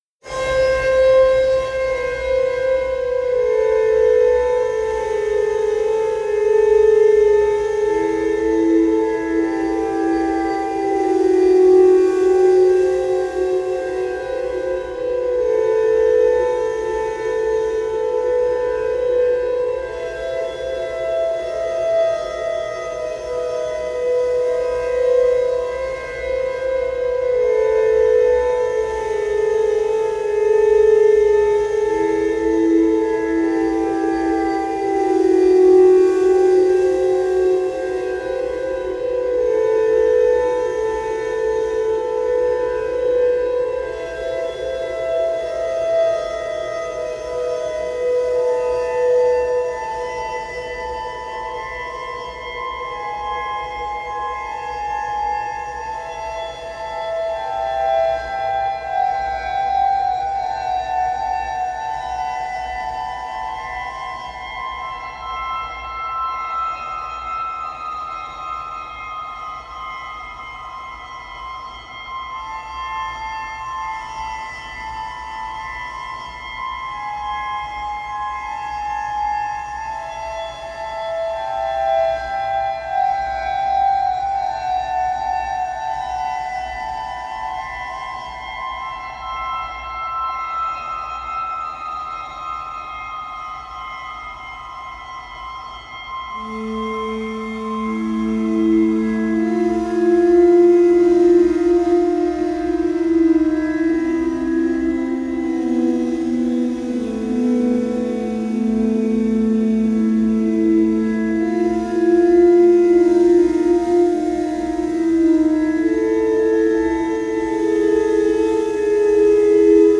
【BPM:80】